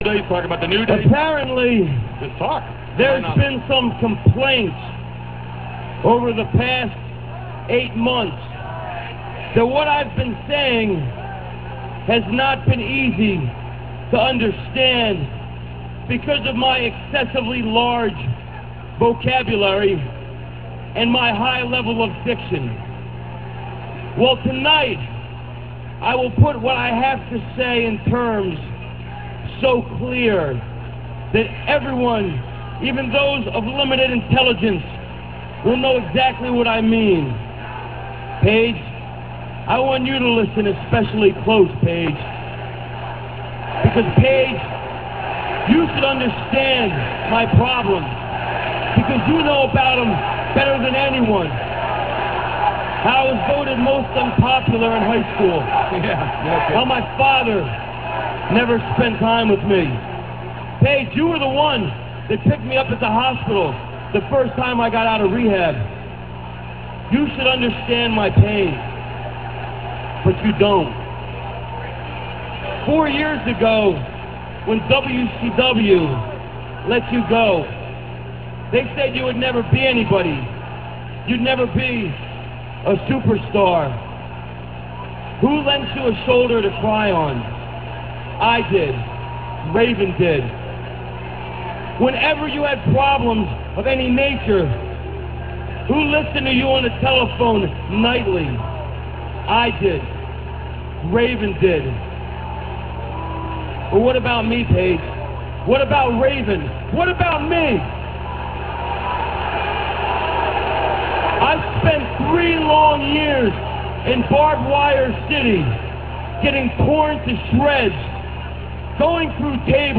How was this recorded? - This speech comes from WCW Nitro - [3.30.98].